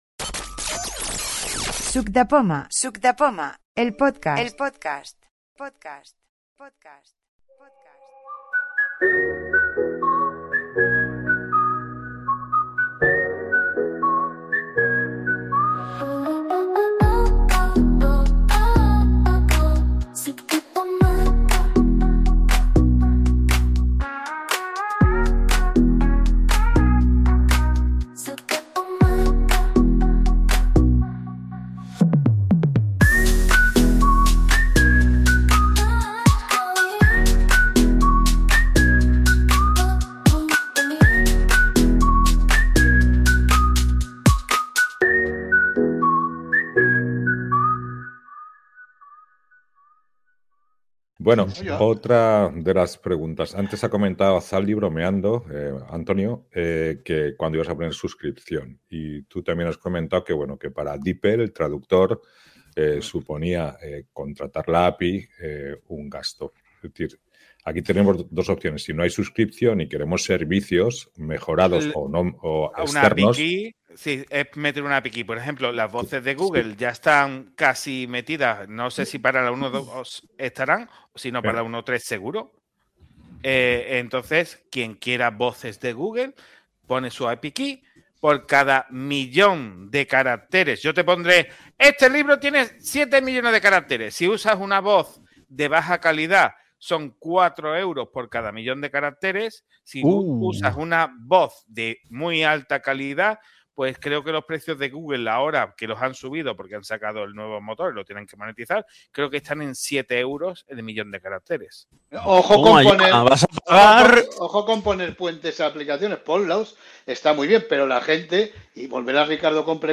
Quedada de noviembre 2023